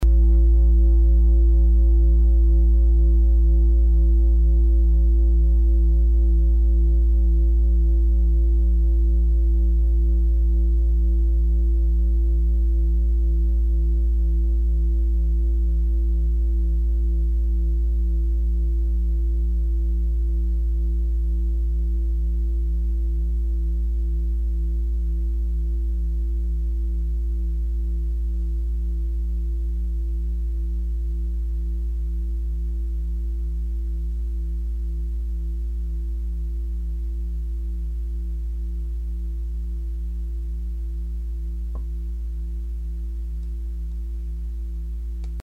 Die große Klangschale wurde in Handarbeit von mehreren Schmieden im Himalaya hergestellt.
Fuß-Klangschale Nr.12
Hörprobe der Klangschale
Diese Frequenz kann bei 224Hz hörbar gemacht werden, das ist in unserer Tonleiter nahe beim "A".
fuss-klangschale-12.mp3